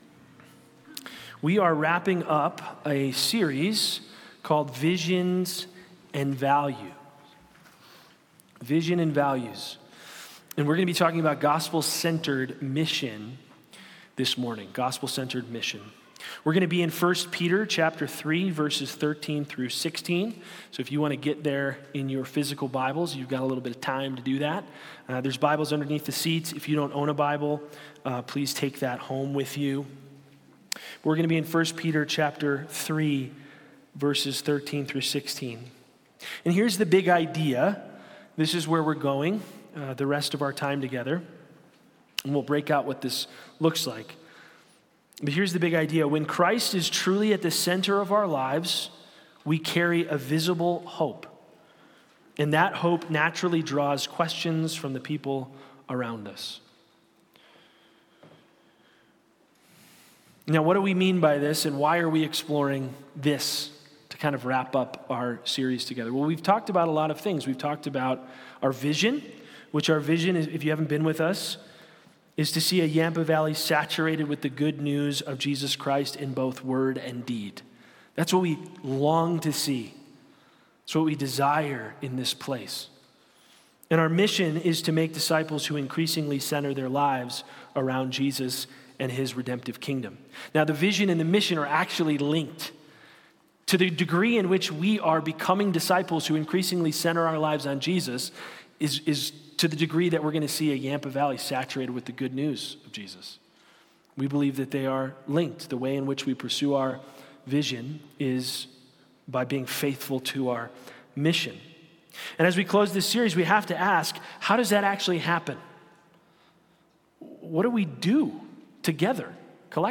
Sermons | Anchor Way Church